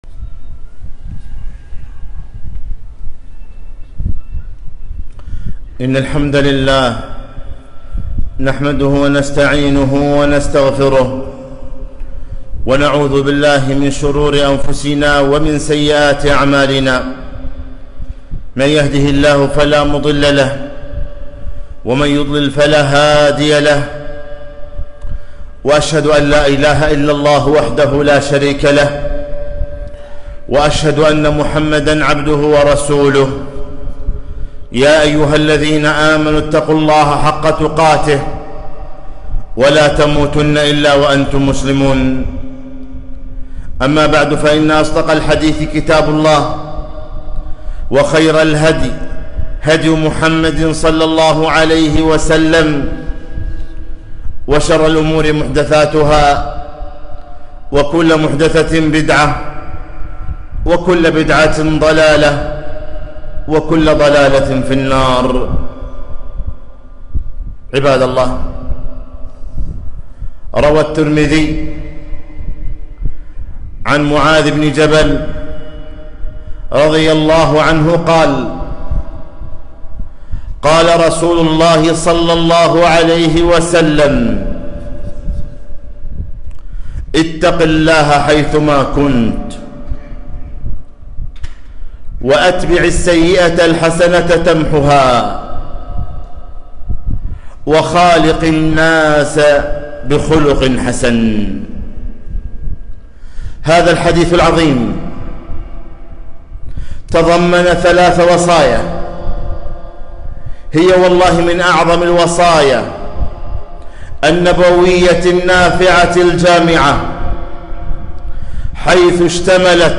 خطبة - وصايا نبوية في أصول المعاملة